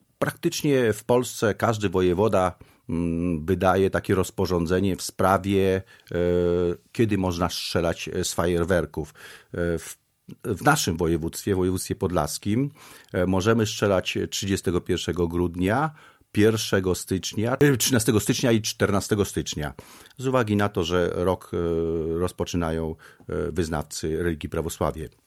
gość Radia 5